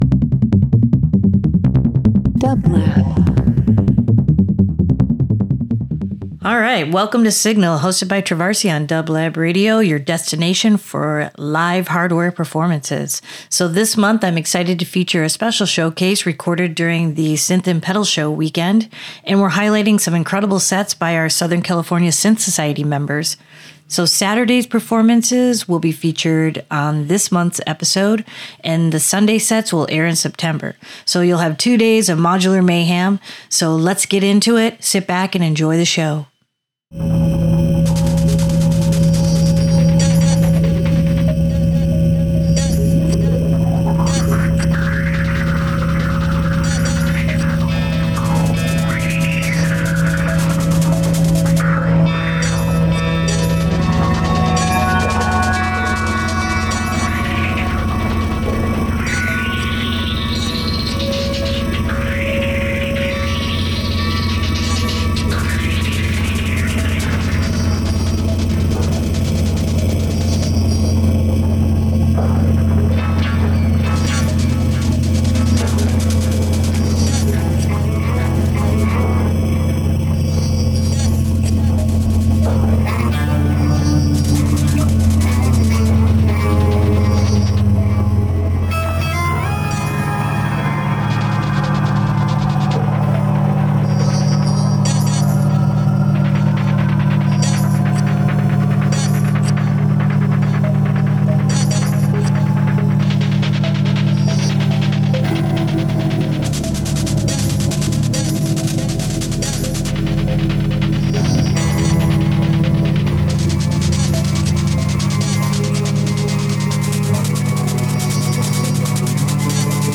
Electronic Experimental Synth